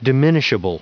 Prononciation du mot diminishable en anglais (fichier audio)
Prononciation du mot : diminishable